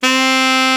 Index of /90_sSampleCDs/Roland LCDP07 Super Sax/SAX_Alto Short/SAX_A.ff 414 Sh
SAX A.FF C05.wav